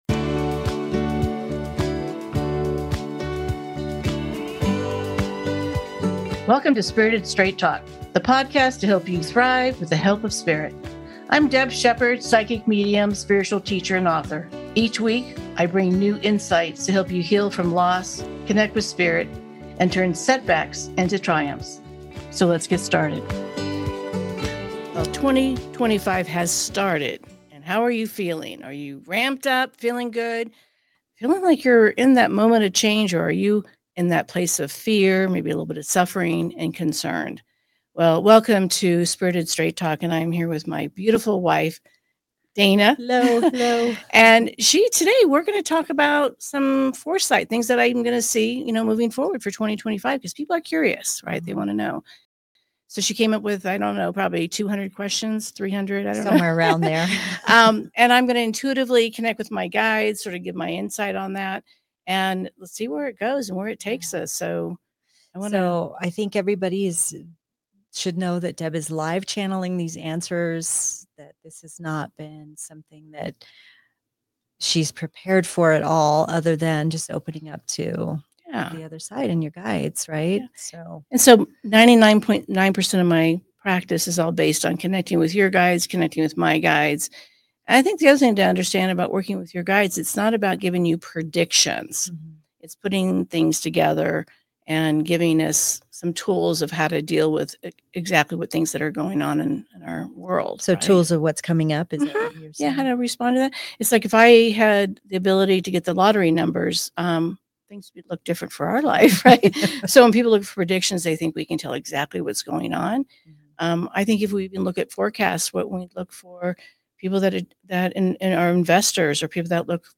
I’ll be live-channeling insights from my guides to give you a look at the energy shifts, global changes, and spiritual transformations coming our way. From economic shifts to leadership changes, the rise of divine feminine energy to the evolution of human consciousness—there’s a lot happening!